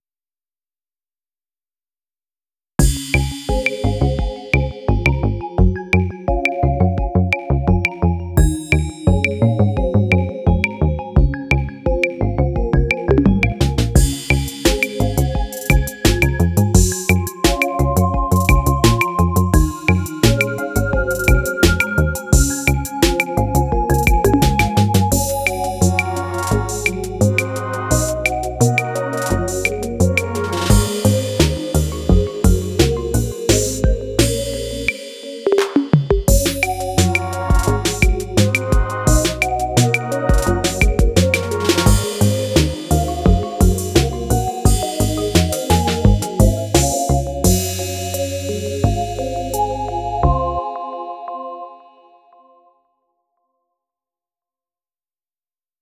↓これはインスト。